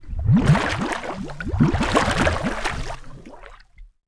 Water_26.wav